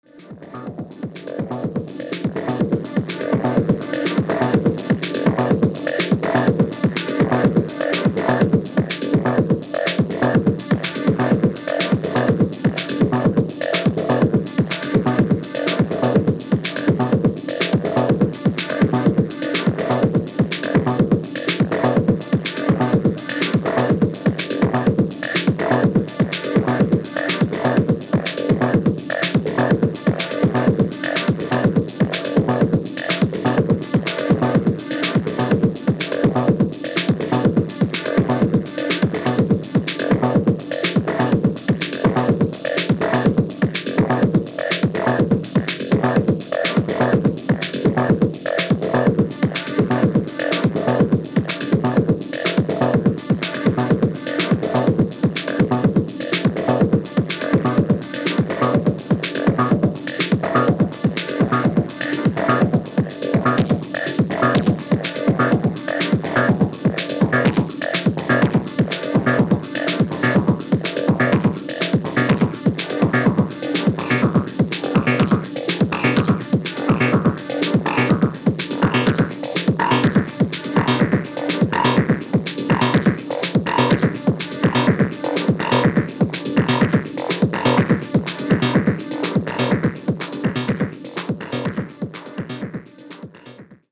進化したACID,DEEP HOUSE感たまんないですね！！！